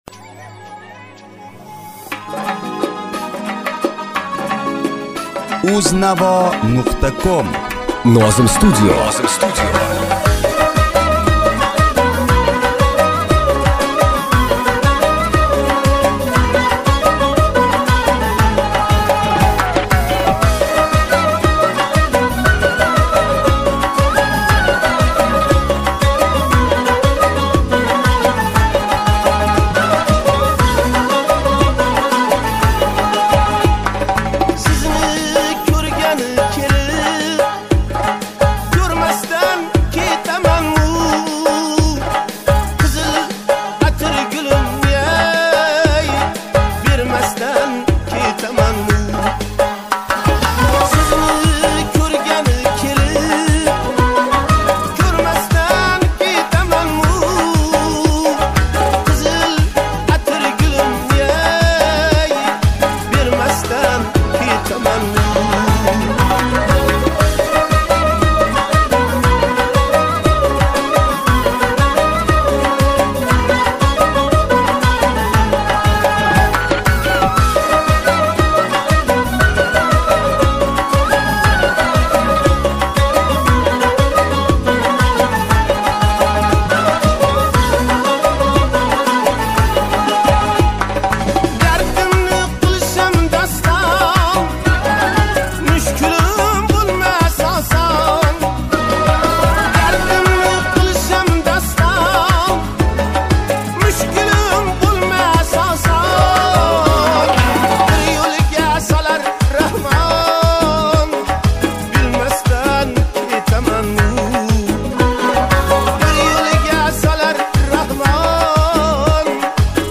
UZBEK MUSIC [7816]
minus